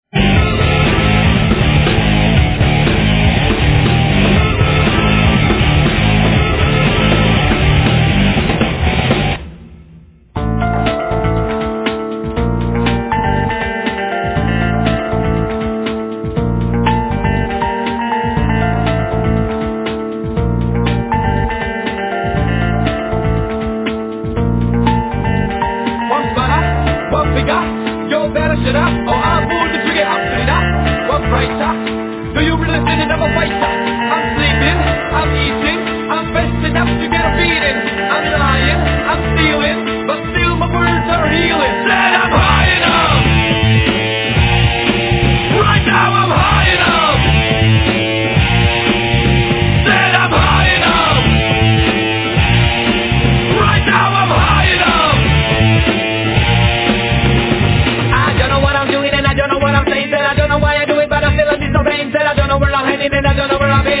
クールなクラブサウンドに時折絡むヘヴィーでパンキーなロックサウンドも非常にかっこいい！